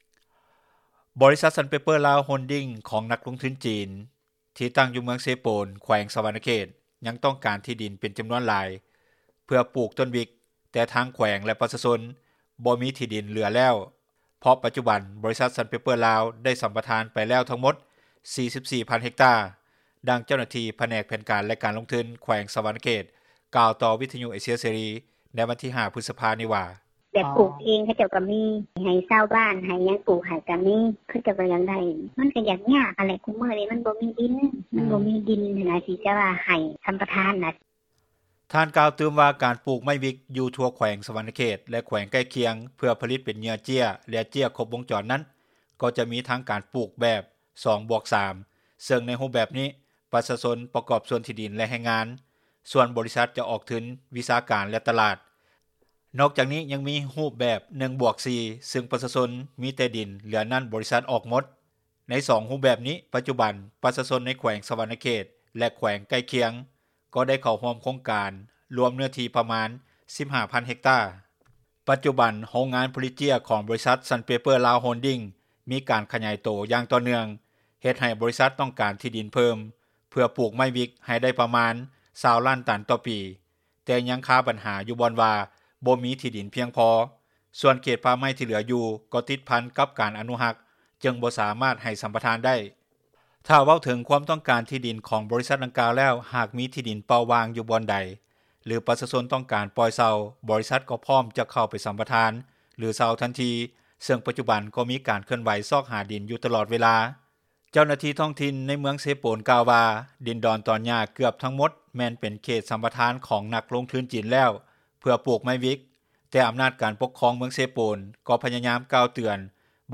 ດັ່ງຊາວບ້ານຜູ້ນີ້ ກ່າວຕໍ່ວິທຍຸ ເອເຊັຽເສຣີ ໃນມື້ດຽວກັນນີ້ວ່າ: